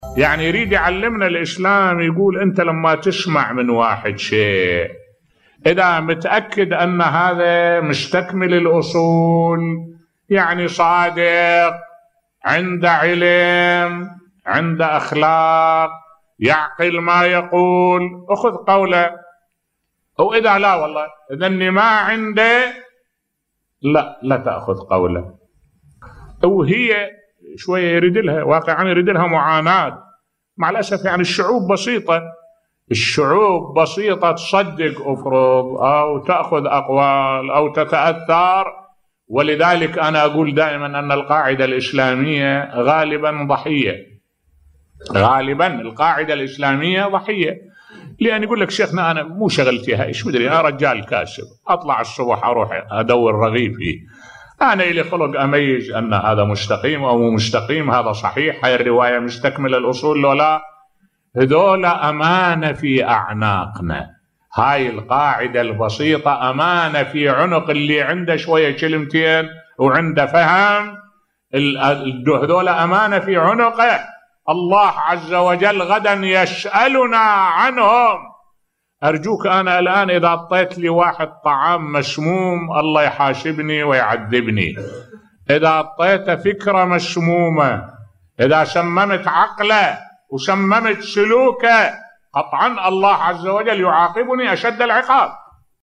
ملف صوتی الطبقة البسيطة أمانة في أعناق العلماء والمثقفين بصوت الشيخ الدكتور أحمد الوائلي